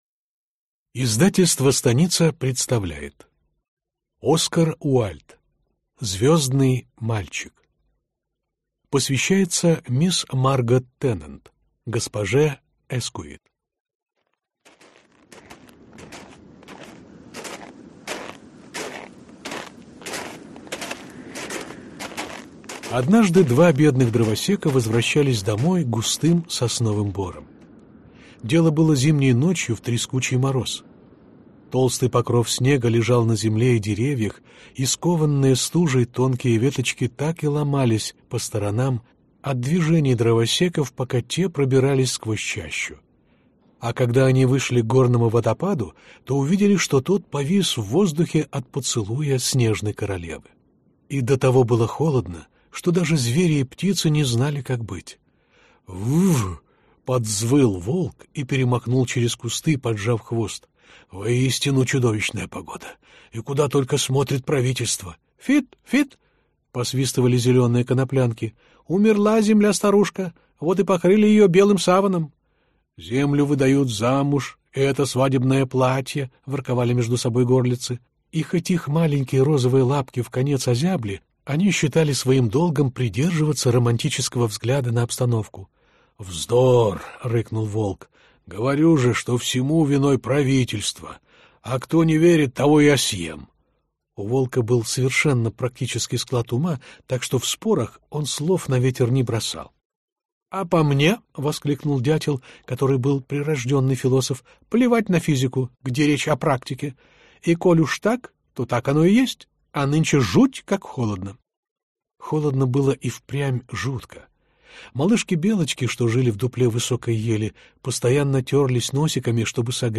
Аудиокнига Звёздный мальчик | Библиотека аудиокниг